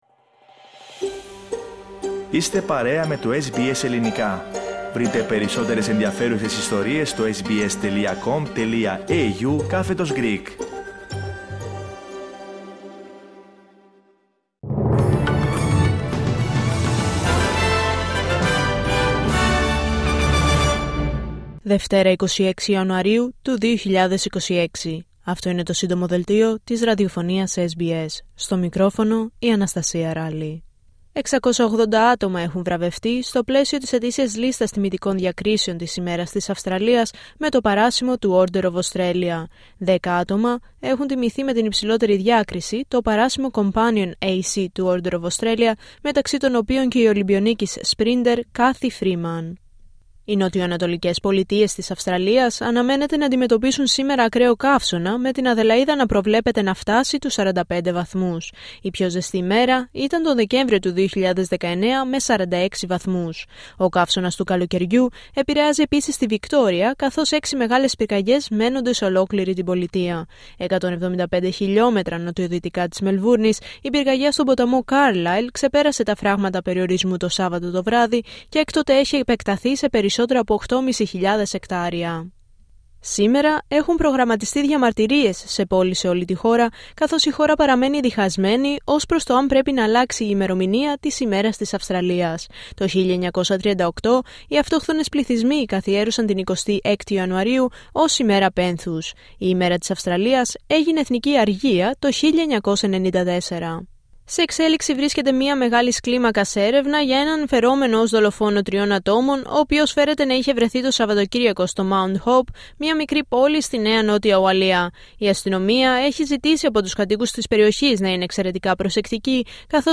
H επικαιρότητα έως αυτή την ώρα στην Αυστραλία, την Ελλάδα, την Κύπρο και τον κόσμο στο Σύντομο Δελτίο Ειδήσεων της Δευτέρας 26 Ιανουαρίου 2026.